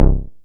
SYNTH BASS-2 0003.wav